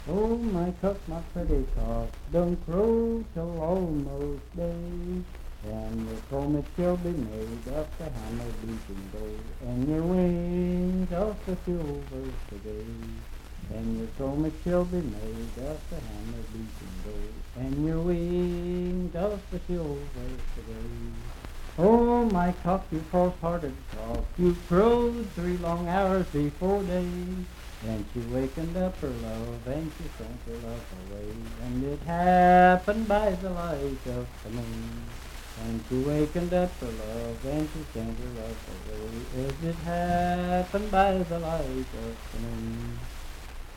Unaccompanied vocal music
Bawdy Songs
Voice (sung)